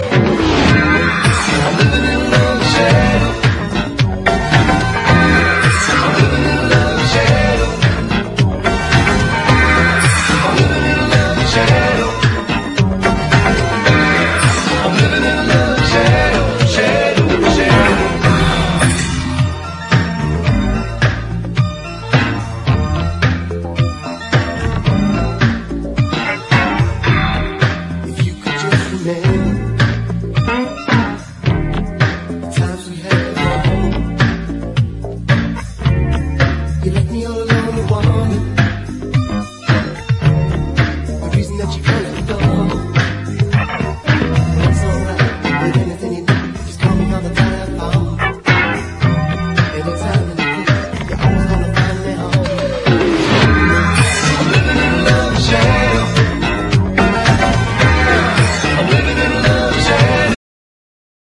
EASY LISTENING / EASY LISTENING / 60'S BEAT
ロッキンなギターとドリーミィなストリングス・オーケストラのポップな融合！